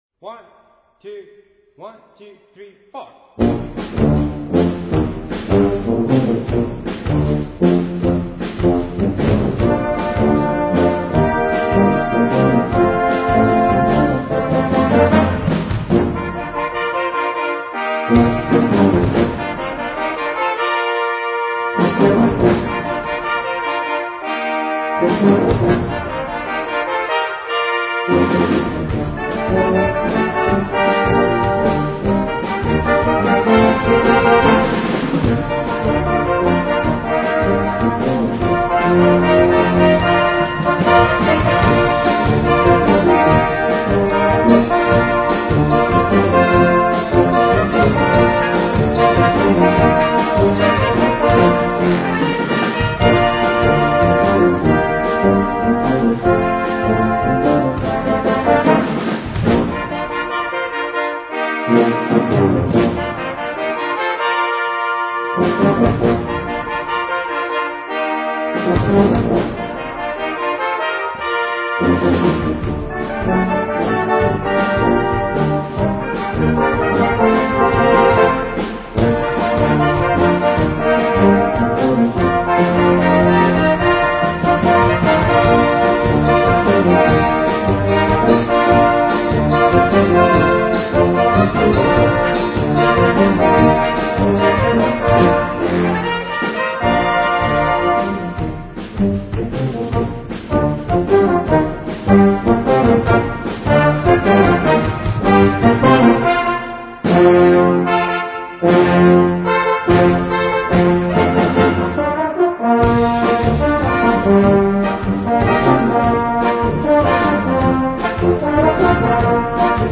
8:00 Minuten Besetzung: Blasorchester PDF